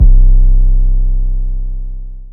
YM 808 15.wav